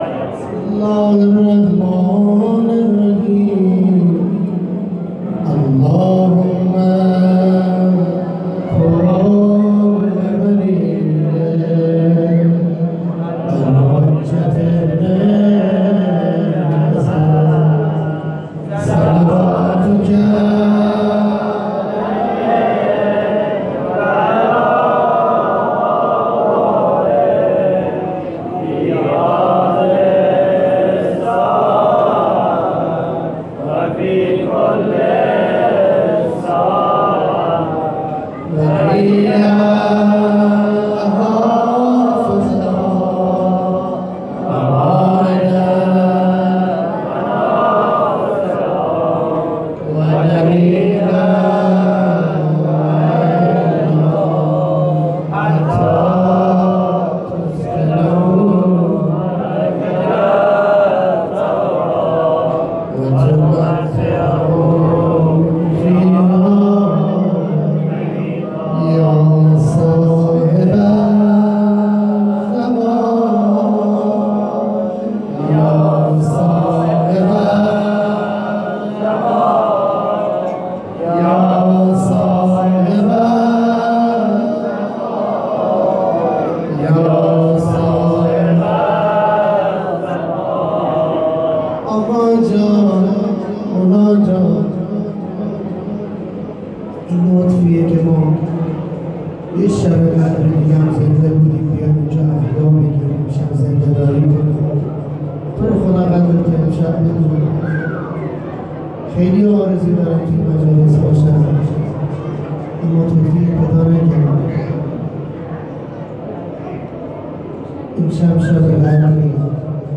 مناجات با امام زمان (عج)
1-مناجات-با-امام-زمان-عج.mp3